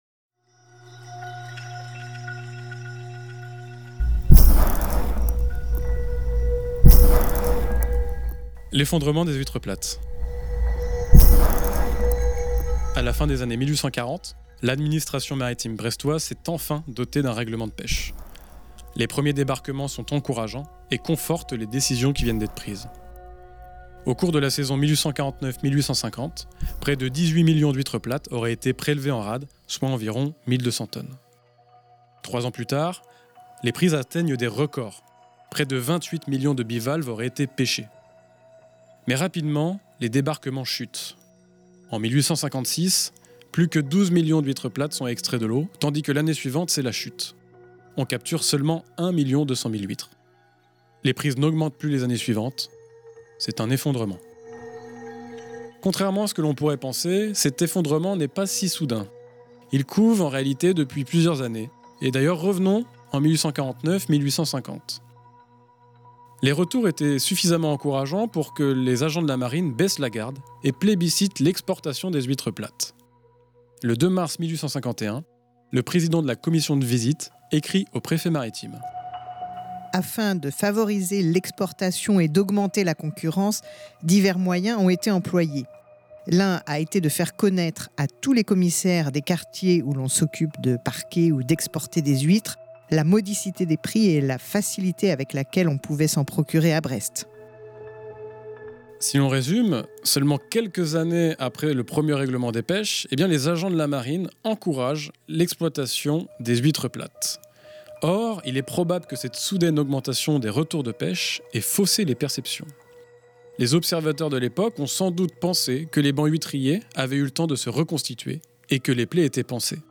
Voici donc le format radio de ce travail, une série de chroniques de quelques minutes à écouter une fois par semaine, le mercredi en début de Lem.